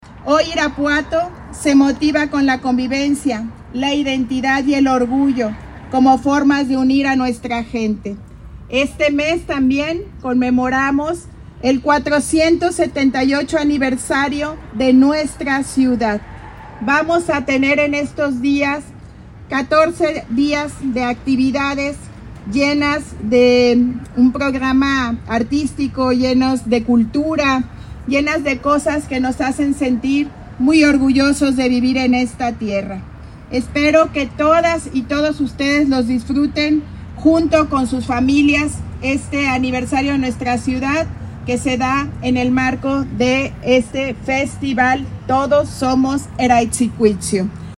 En esta ocasión, en el izamiento de la bandera monumental, estuvieron presentes autoridades y estudiantes de la primaria Emiliano Zapata, quienes participaron de manera entusiasta en la ceremonia y dieron a conocer las efemérides.
Lorena Alfaro García, presidenta municipal